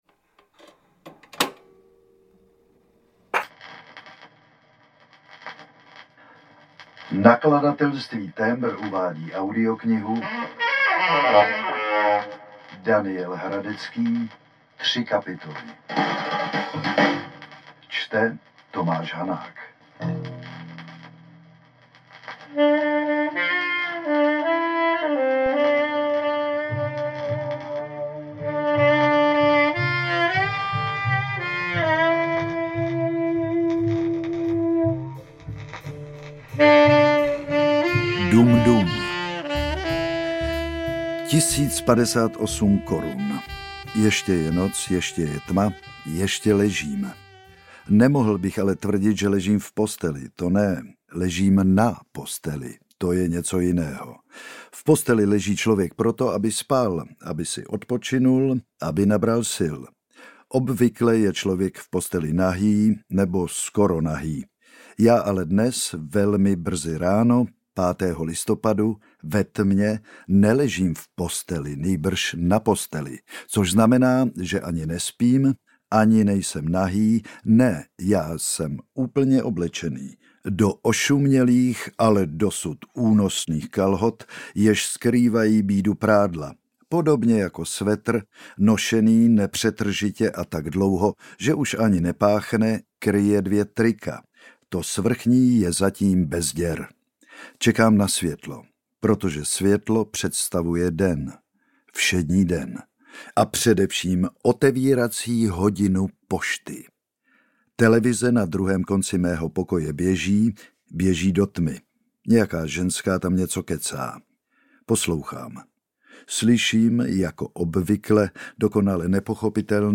Tři kapitoly audiokniha
Ukázka z knihy
• InterpretTomáš Hanák